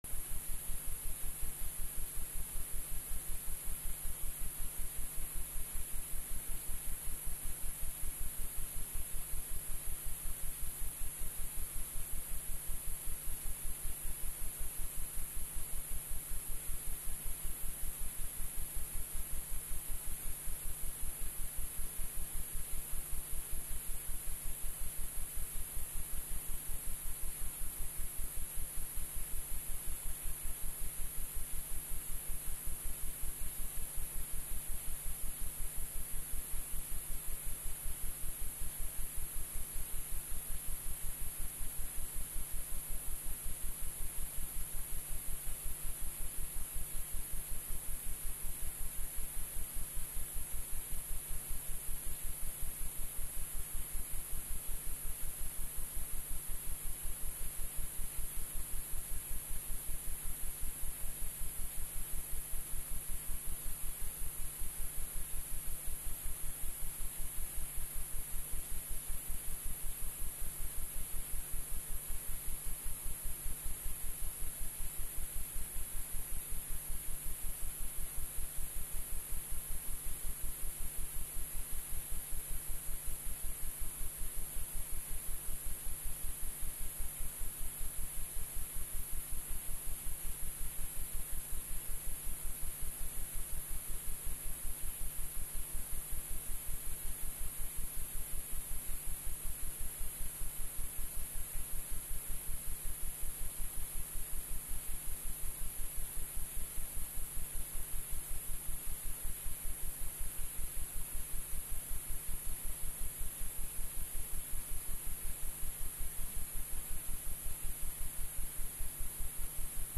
（1）MIC3L/MIC3R输入线性音频，直接绑定HPLCOM/HPRCOM，发现有明显的底噪，